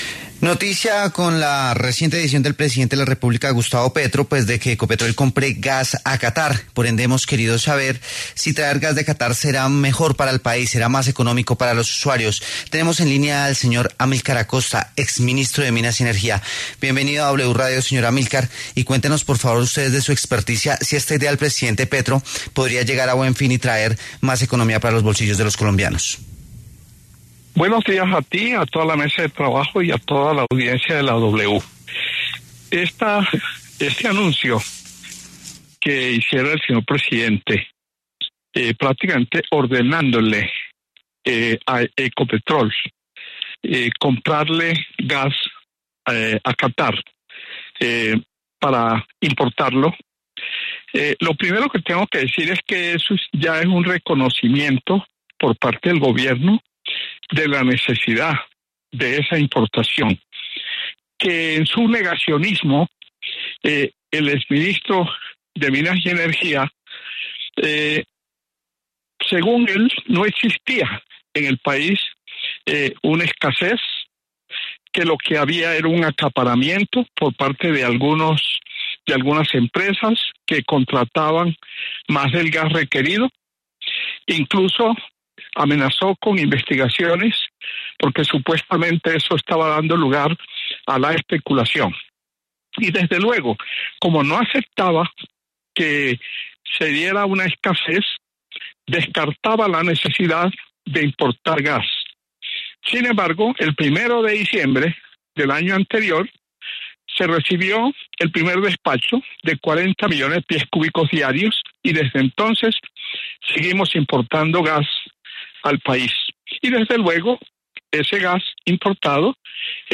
En La W, Amylkar Acosta, exministro de Minas y Energía, cuestionó la idea del presidente Gustavo Petro de traer gas desde Qatar y desestimó que pueda representar un ahorro para los colombianos.